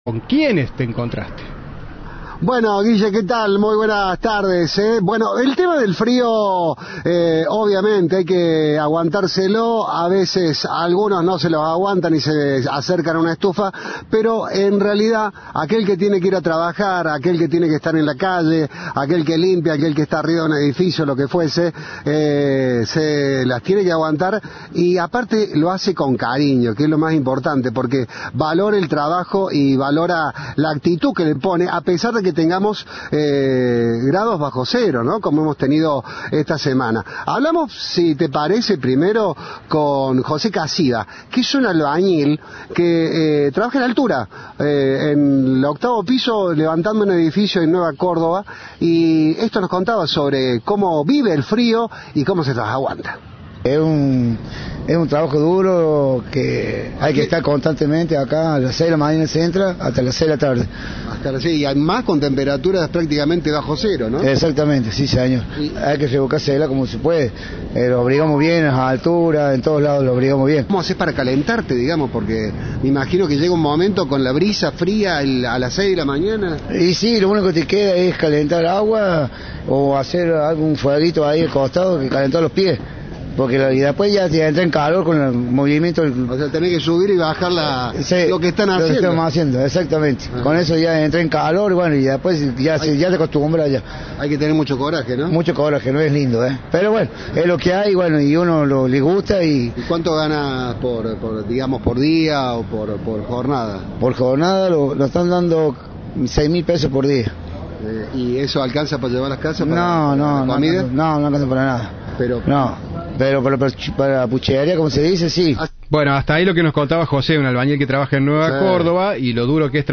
Trabajadores de Córdoba contaron a Cadena 3 cómo se las arreglan para soportar el frío.
Informe